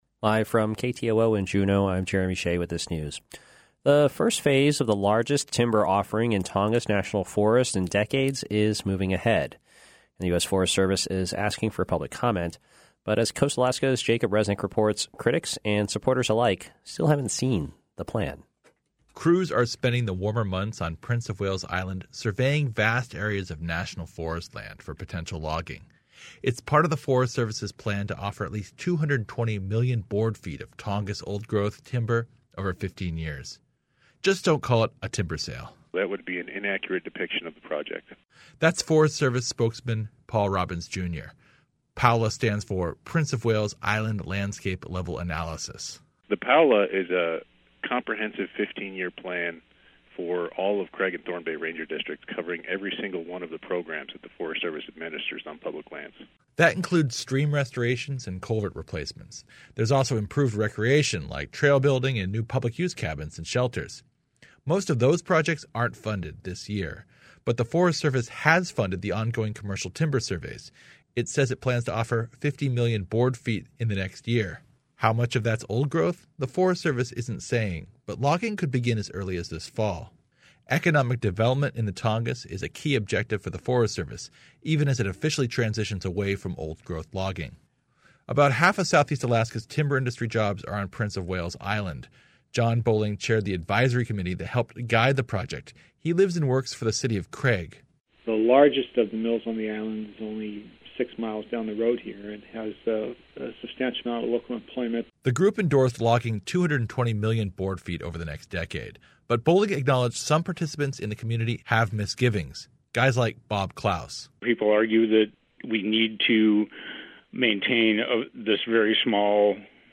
Newscast – Thursday, April 25, 2019